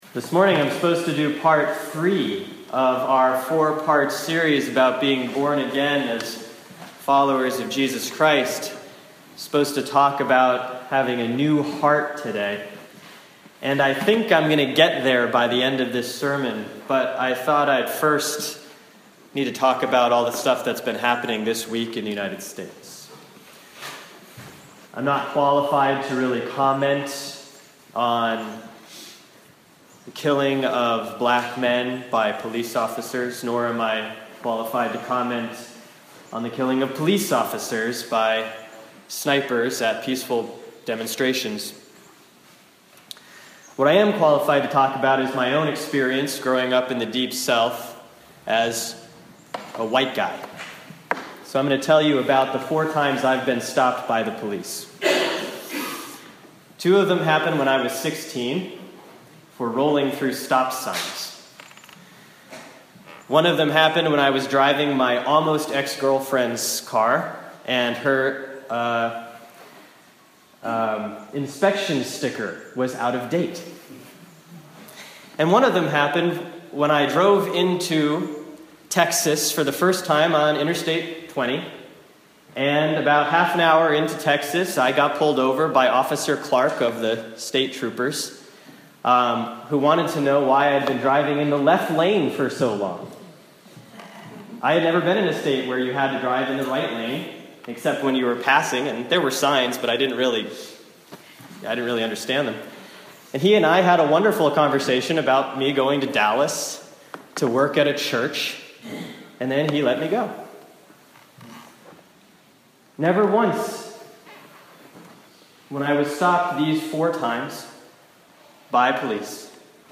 Sermon for Sunday, July 10, 2016 || Proper 10C || Luke 10:25-37
During the summer, I am preaching without a text, so what follows is an edited transcript of what I said Sunday morning at the 8 a.m. service at St. Mark’s.